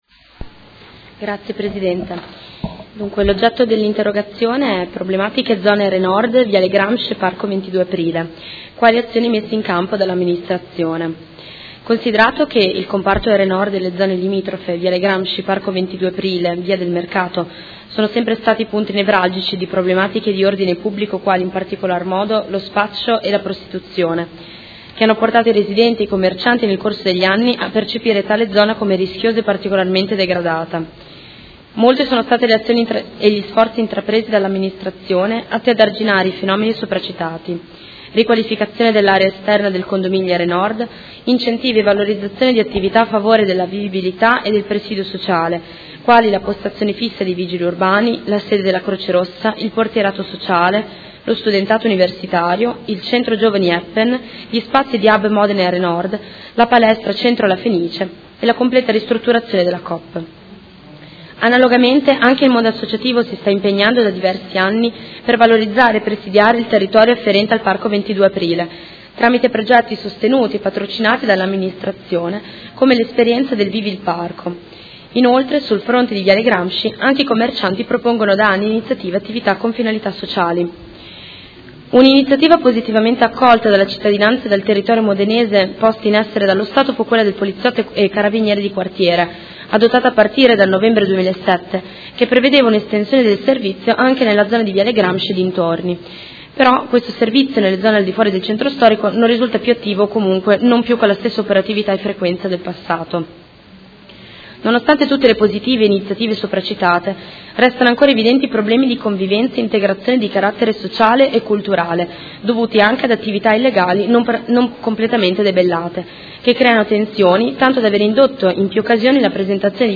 Seduta del 16/02/2017. Interrogazione dei Consiglieri Venturelli, Stella e Carpentieri (P.D.) avente per oggetto: Problematiche zona R-nord - Viale Gramsci – e Parco XII Aprile.